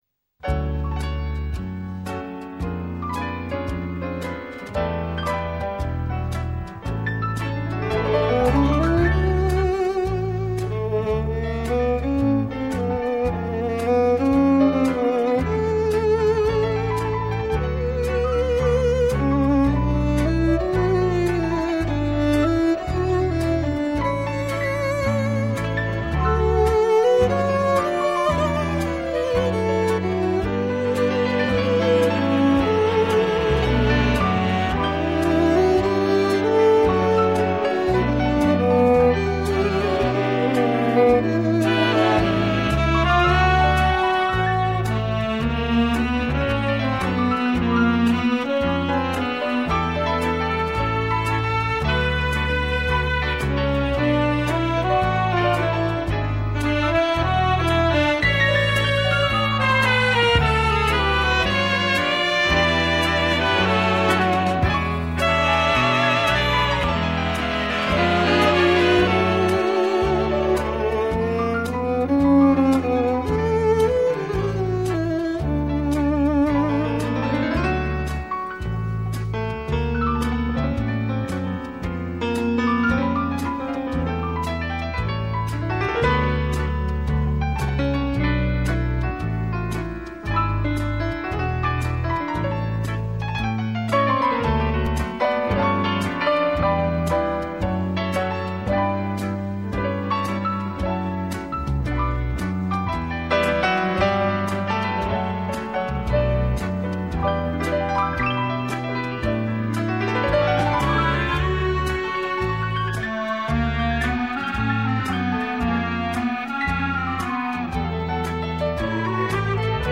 大乐队时代的经典之作！